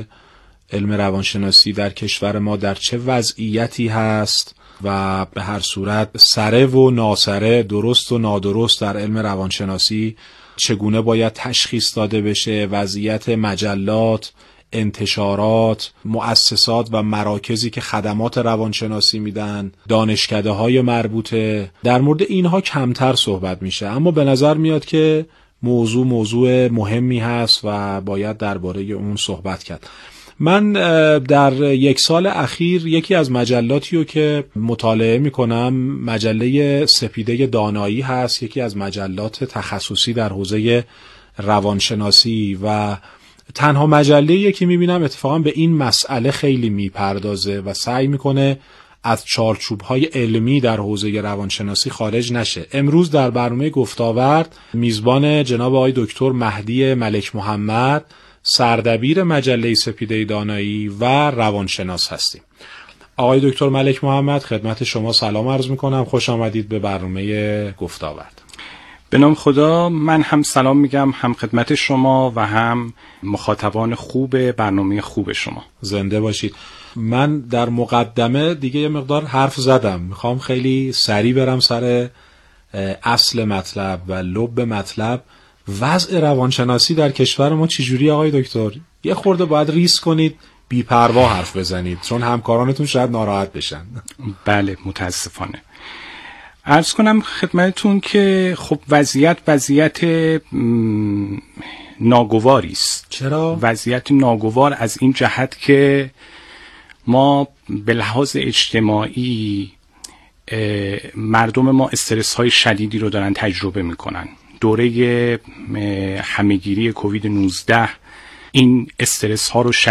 یک روانشناس با حضور در برنامه گفتاورد درباره شاخص‌های یک جلسه روان درمانی استاندارد و همچنین یک روان درمانگر حاذق و مشکلاتی که امروزه در مسیر روان‌درمانی برای شهروندان وجود دارد صحبت کرد.